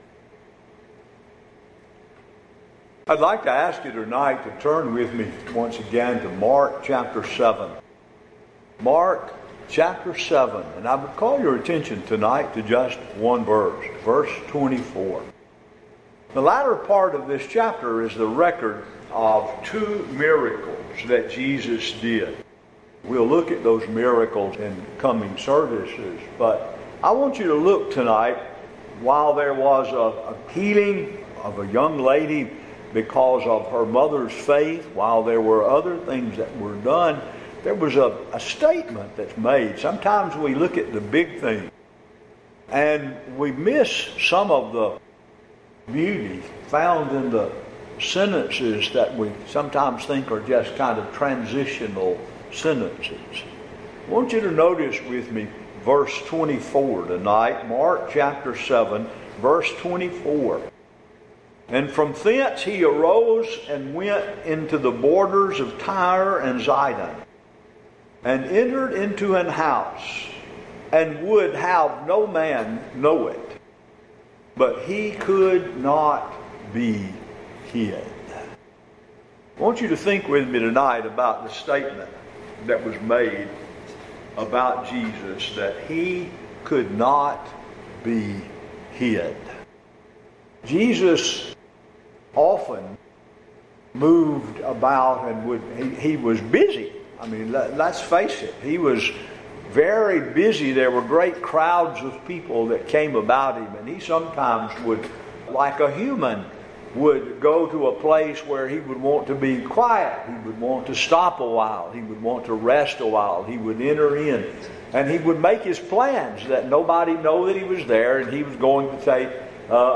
Acts 2:37-47 & Psalm 66:16, All Are Called to Evangelism Sep 1 In: Last 7 Previous Sermons